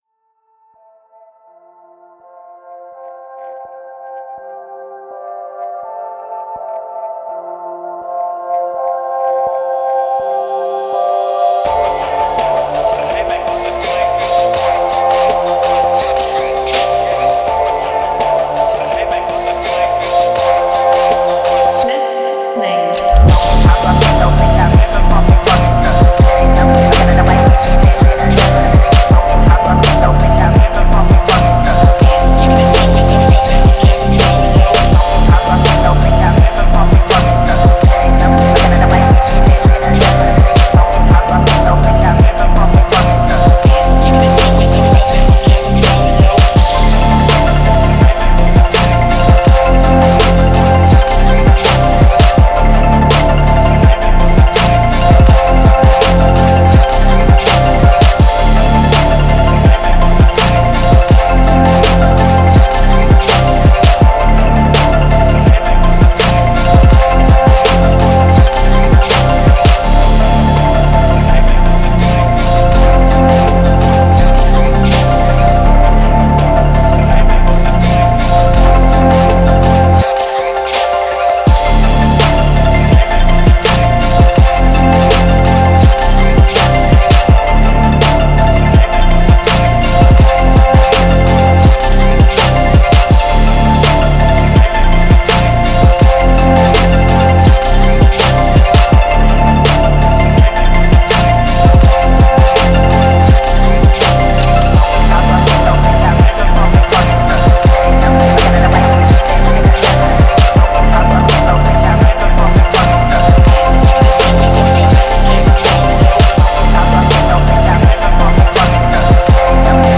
Фонк музыка